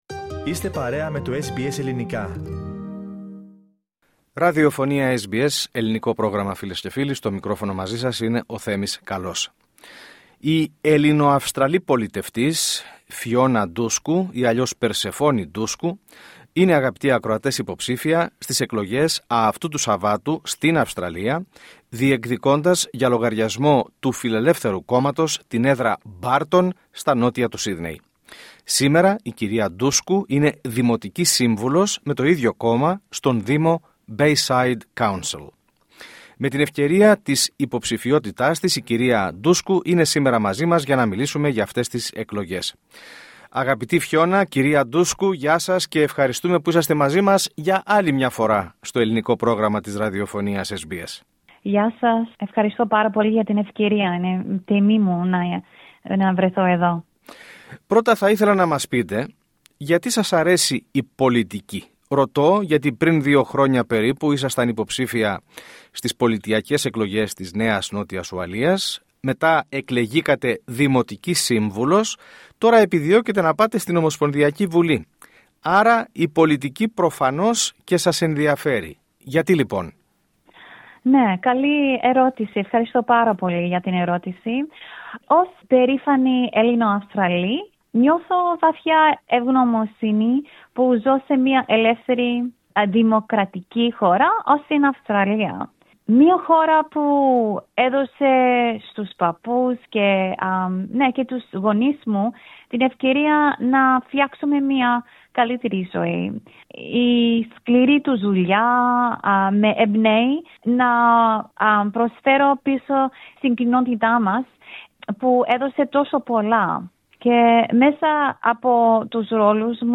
LISTEN TO Φιόνα Ντούσκου- Υποψήφια στην ομοσπονδιακή έδρα Barton 6'.10'' 06:24 Με την ευκαιρία της τωρινής υποψηφιότητάς της η κυρία Ντούσκου μίλησε στο Πρόγραμμά μας, SBS Greek. Μας μίλησε για τις προκλήσεις που αντιμετωπίζει σήμερα και το πως το κόμμα της θα συμβάλλει στην αντιμετώπισή τους, εστίασε σε συγκεκριμένα μέτρα και πολιτικές των Φιλελευθέρων για την αντιμετώπιση του κόστους ζωής και αναφέρθηκε στο πως η εμπειρία της στην Τοπική Αυτοδιοίκηση μπορεί να την βοηθήσει αν καταφέρει να αναδειχθεί ομοσπονδιακή βουλευτής.